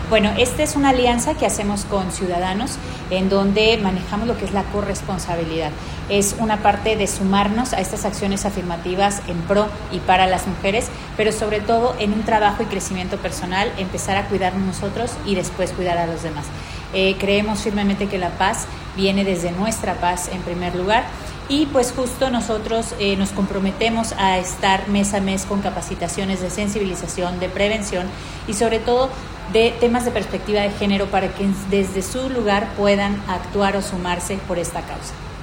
Georgina Aboyetes Guerero, directora del Inmira